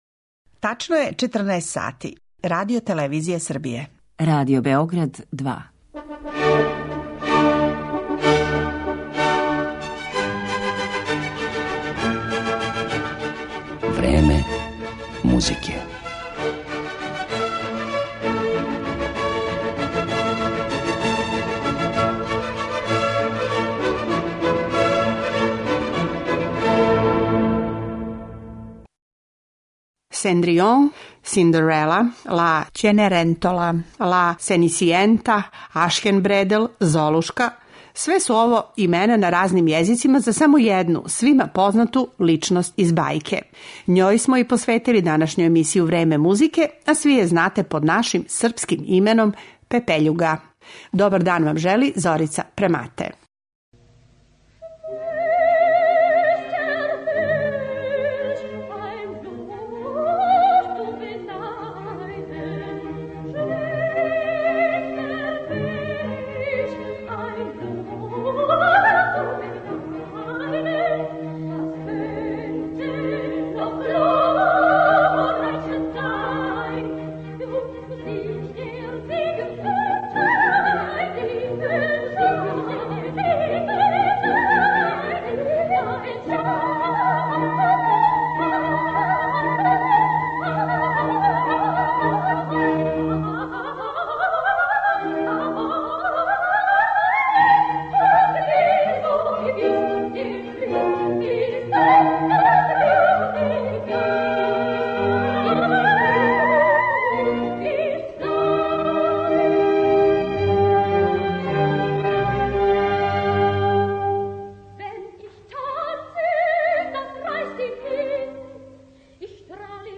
Слушаћете како су Пепељугу у својој оперској или балетској музици дочарали, између осталих композитора, Росини, Масне, Штраус-син и Прокофјев.